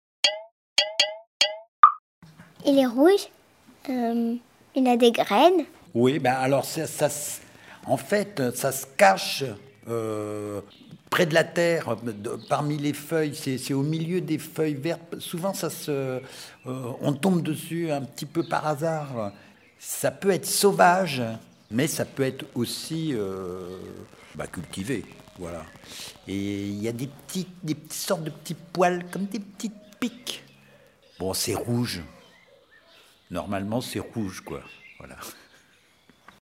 …des devinettes sonores